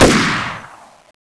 assets/common/sounds/weapons/stg/shoot.wav at 78f0abe57798c6b29d69af41980c245b25dadb61
shoot.wav